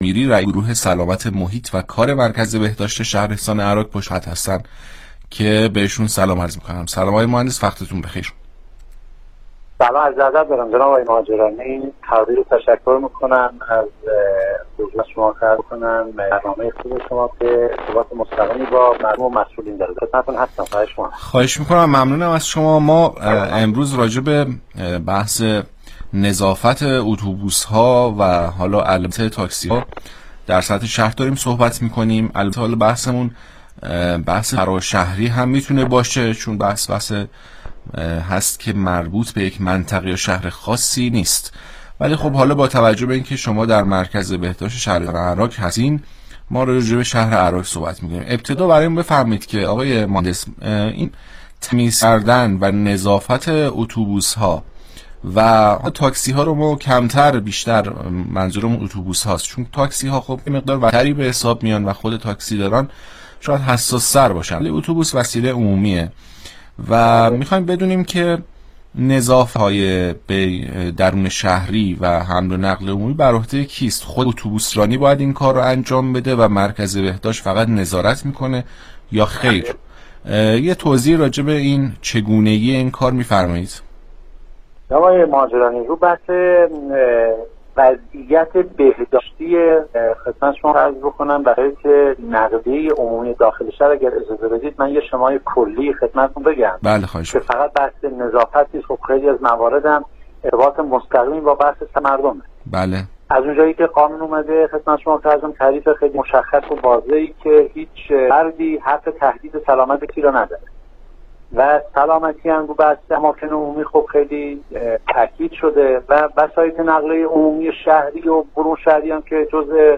برنامه رادیویی پاسخ با موضوع بهداشت و نظارت اتوبوس ها و تاکسی ها - معاونت بهداشتی